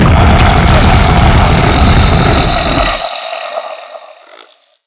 death1.wav